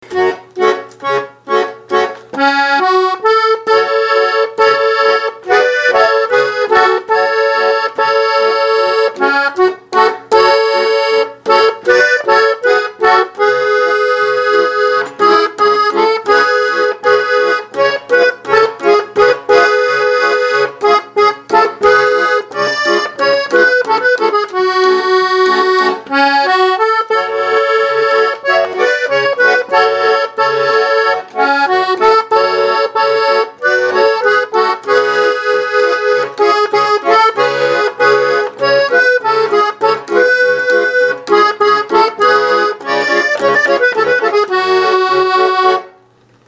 По-моему, там всего два аккорда: Ля минор и Ми Септ-аккорд.
Я наиграл по-скорому.